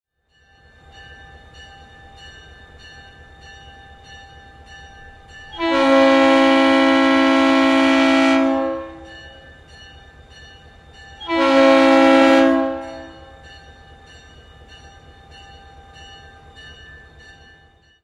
Звуки тепловоза
Звуки сигналов тепловоза: локомотив готов к отправлению